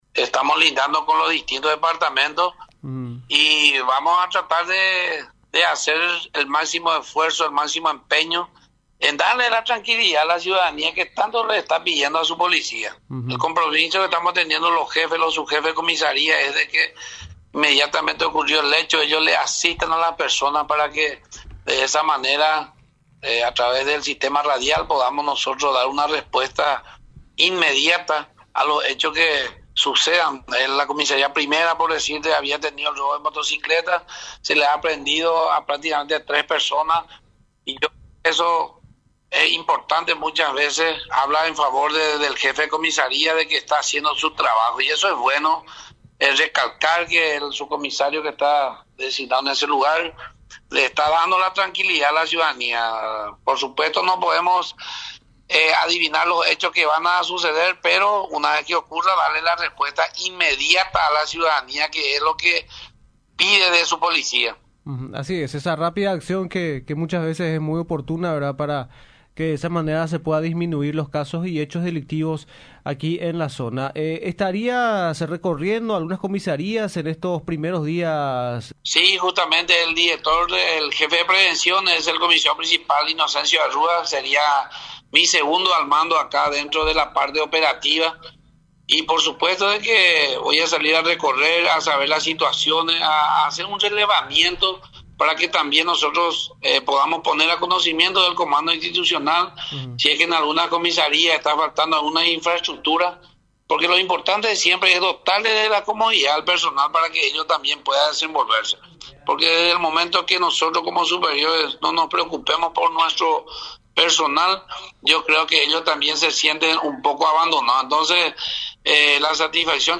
El nuevo director en conversación con Radio Nacional, comentó acerca de las principales acciones que serán encaradas en esta región desde la institución a su cargo, por lo que señaló que, primeramente, realizará un recorrido para verificar las instalaciones y realidad de las diferentes comisarías del segundo departamento.